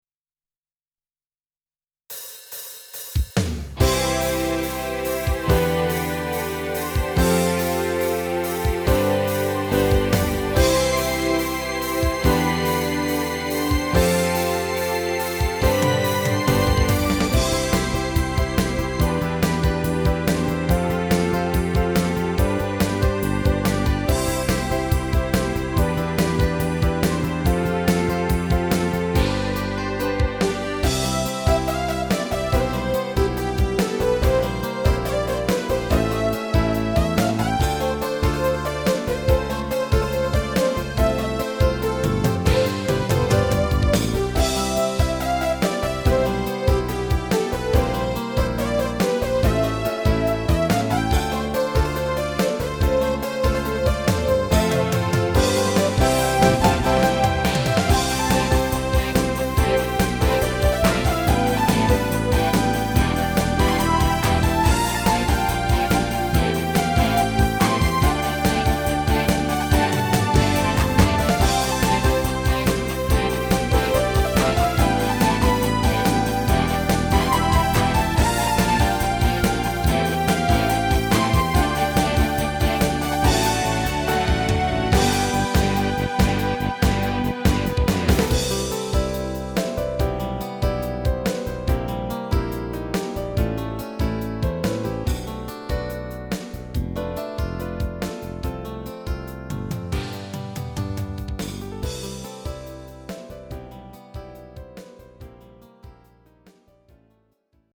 アホアホアレンジ版   GM版